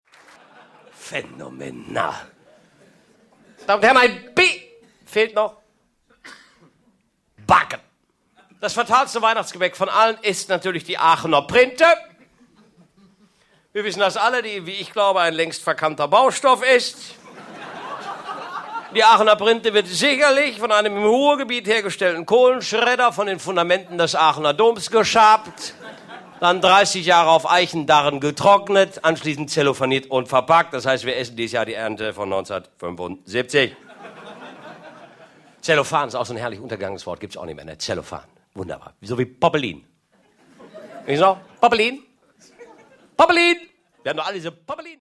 Jochen Malmsheimer (Sprecher)
Zusammen sorgen sie für ein unvergleichliches Weihnachtsprogramm, das für den zarten festlichen Schmelz aber auch die eine oder andere Weihnachtsüberraschung der besonderen Art sorgt.